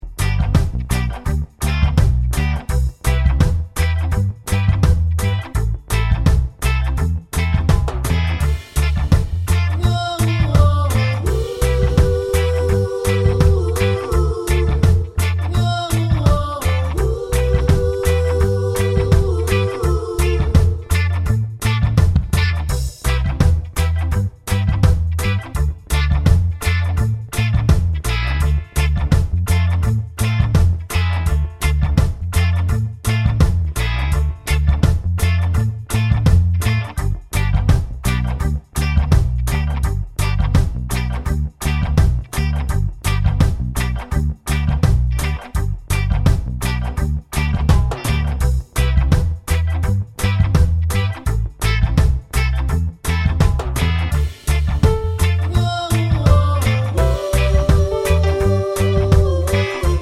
no Backing Vocals Reggae 3:00 Buy £1.50